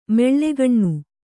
♪ meḷḷegaṇṇu